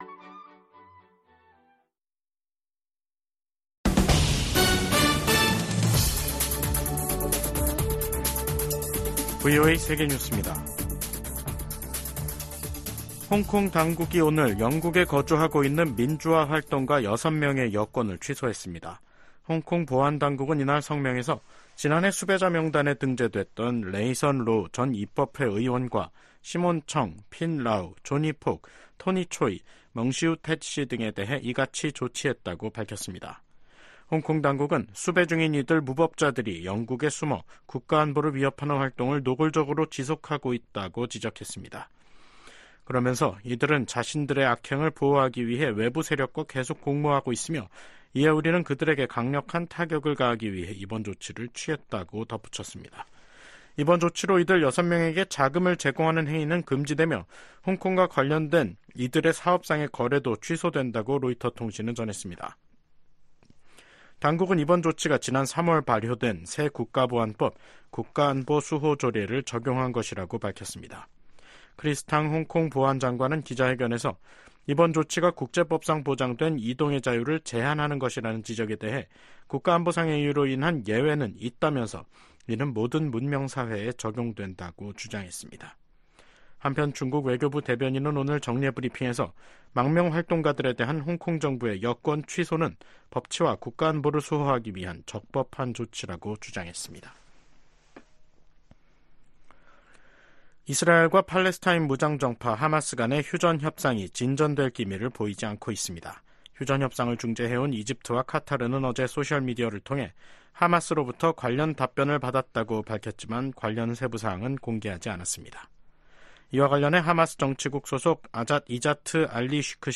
VOA 한국어 간판 뉴스 프로그램 '뉴스 투데이', 2024년 6월 12일 3부 방송입니다. 미국은 오물 풍선 살포 등 한반도 안보와 관련해 “어떤 일에도 준비가 돼있다”고 필립 골드버그 주한 미국대사가 말했습니다. 풍선 살포로 한반도에 긴장이 고조된 가운데 남북한은 확전을 피하기 위해 상황을 관리하려는 움직임을 보이고 있습니다.